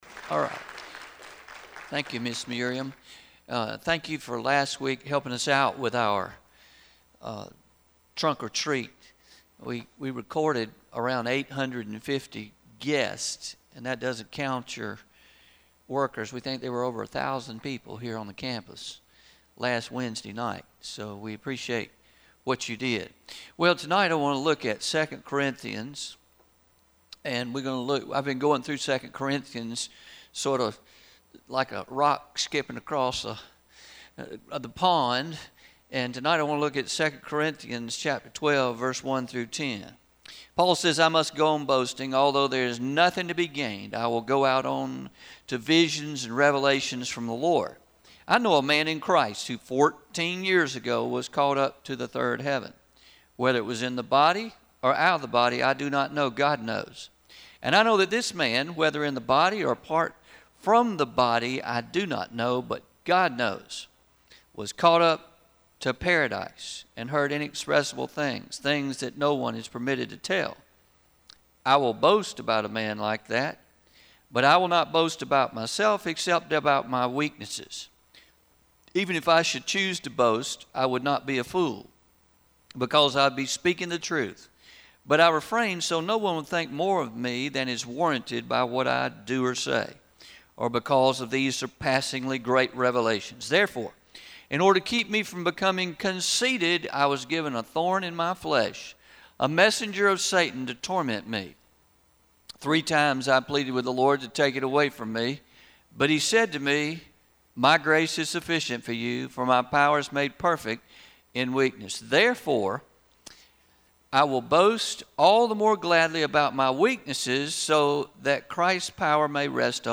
11-06-19pm Sermon – What Happens If It’s Me